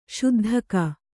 ♪ śuddhaka